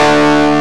Index of /90_sSampleCDs/Roland L-CDX-01/GTR_Distorted 1/GTR_Power Chords
GTR DIST G3.wav